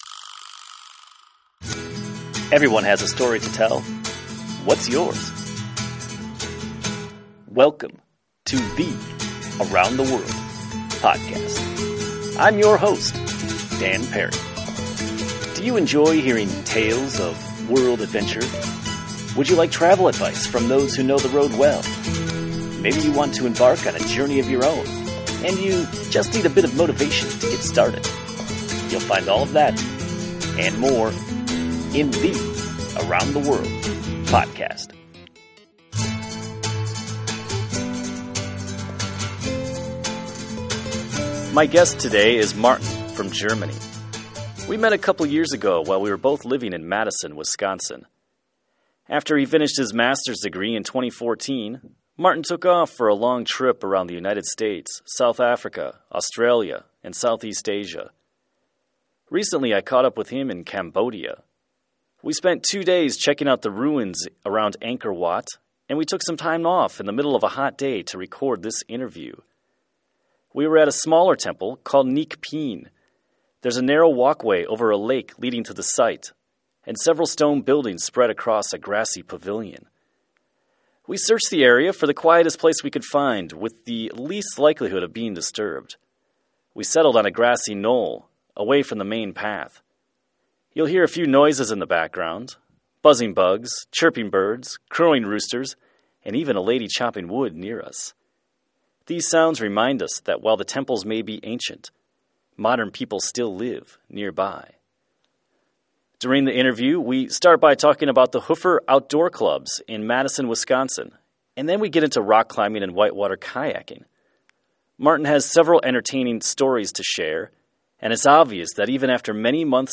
A podcast interview
It was a typical hot day, so we took some time off from our sightseeing to record this interview.